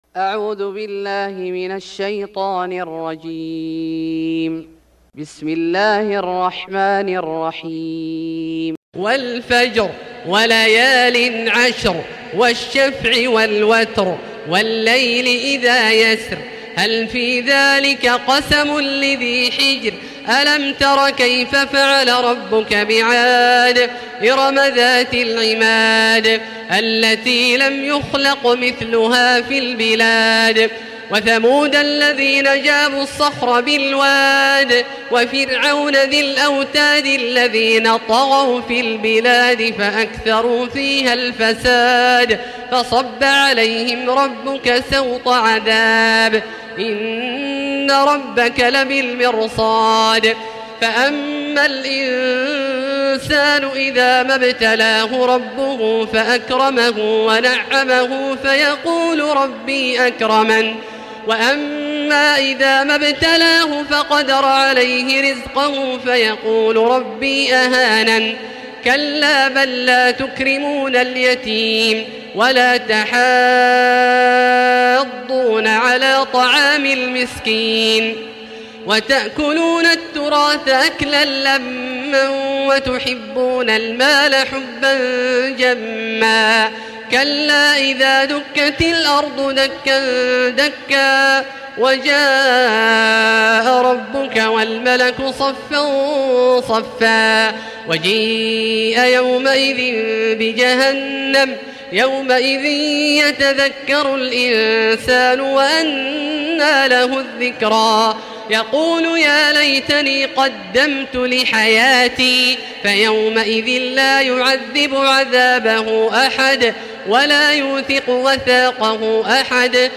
سورة الفجر Surat Al-Fajr > مصحف الشيخ عبدالله الجهني من الحرم المكي > المصحف - تلاوات الحرمين